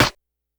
Snares
snr_46.wav